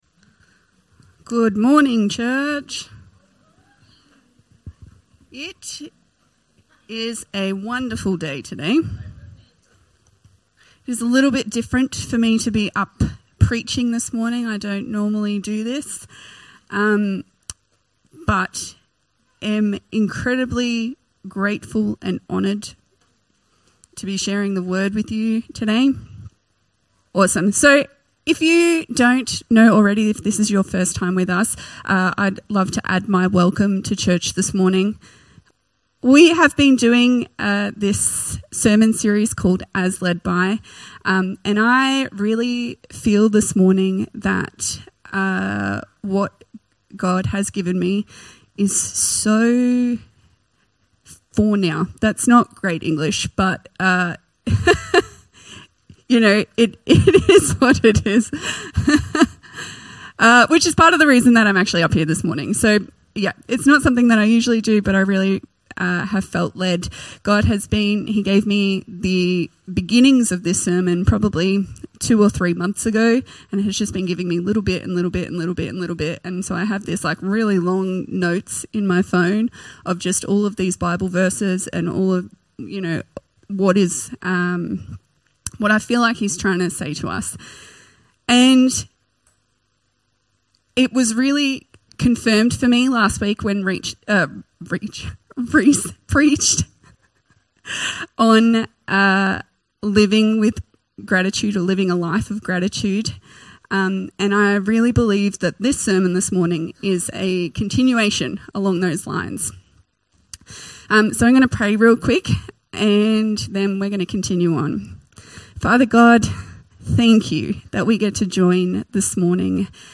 Infinity Church Podcast - English Service | Infinity Church